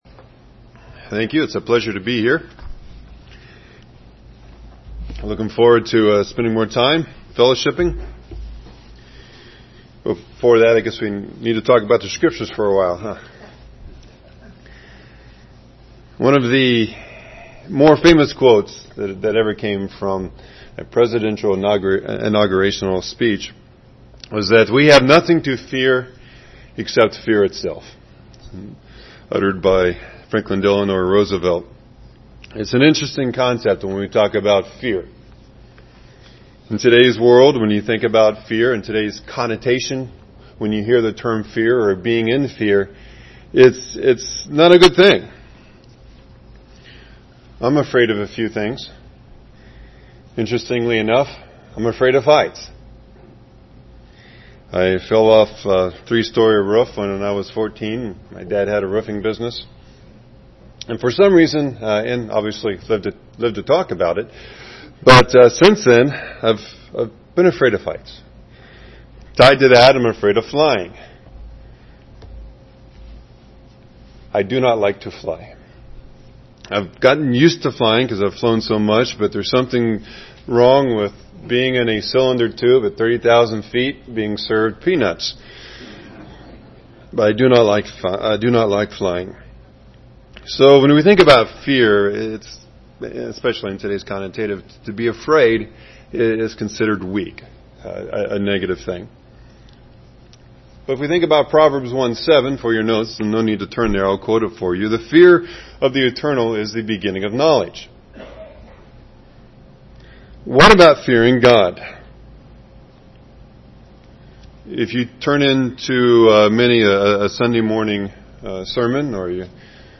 Given in Beloit, WI
UCG Sermon Studying the bible?